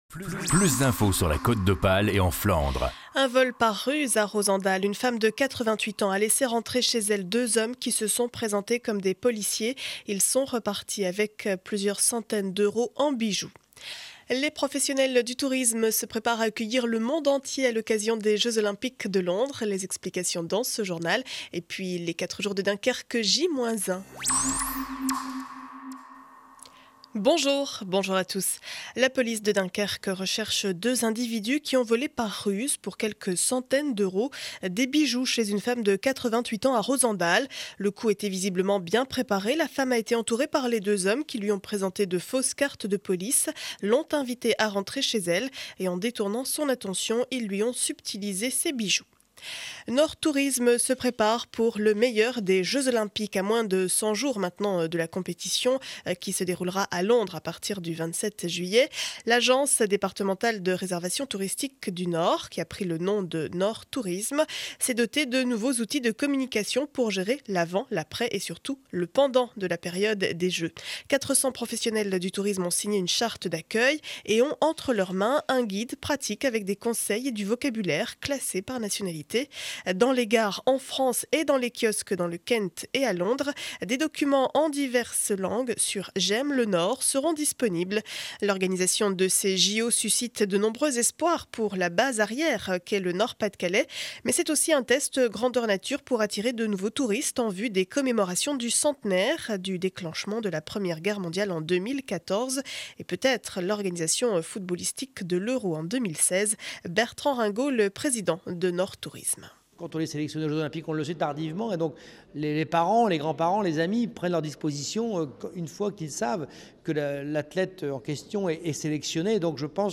Journal du jeudi 03 mai 2012 7 heures 30 édition du Dunkerquois.